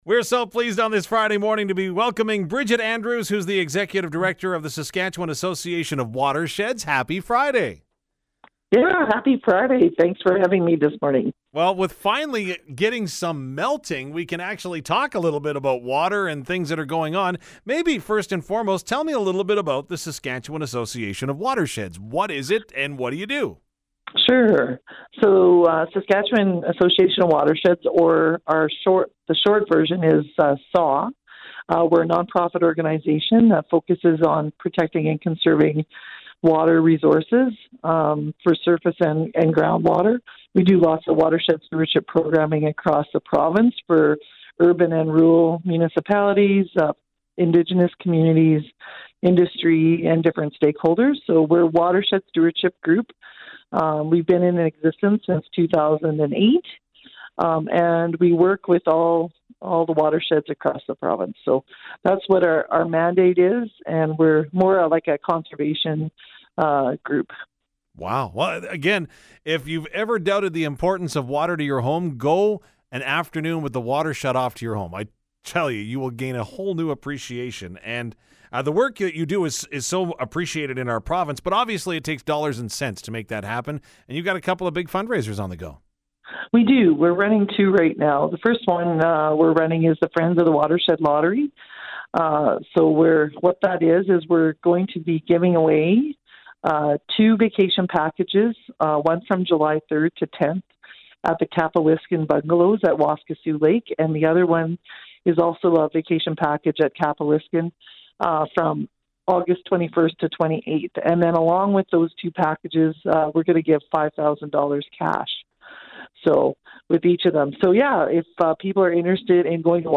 joined the Moo Crew on Friday morning.